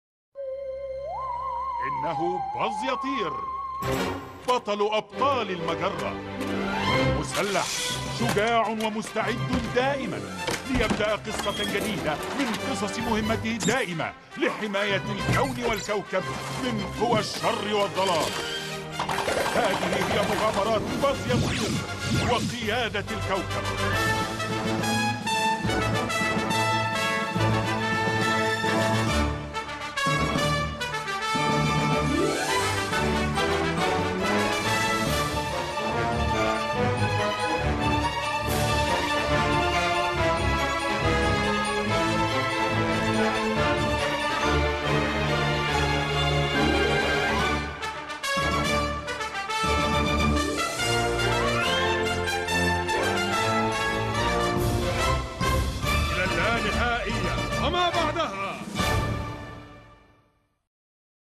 بظ يطير - الحلقة 1 مدبلجة